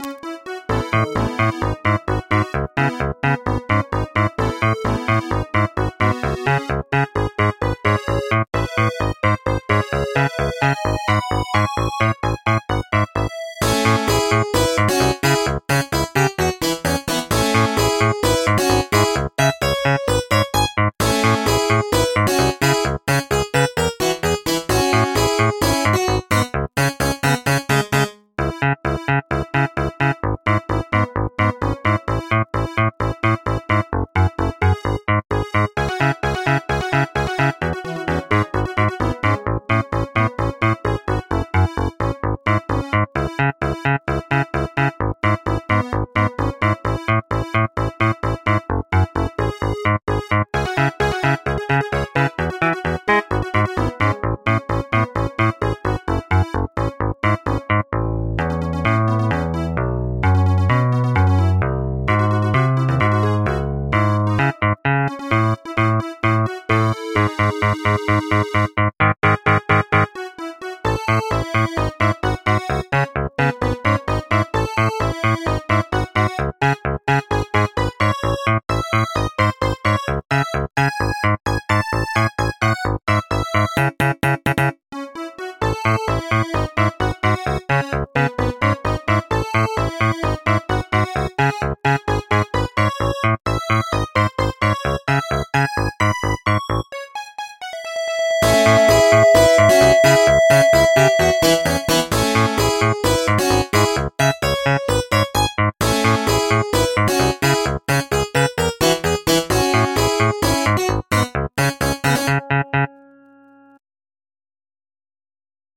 MIDI 106.99 KB MP3